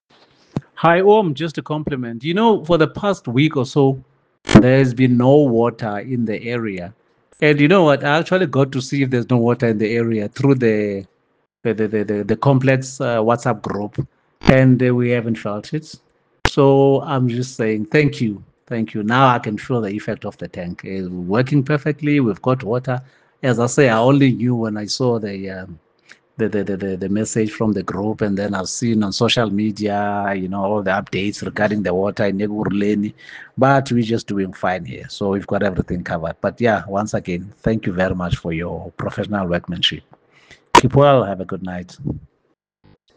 Audio Testimonial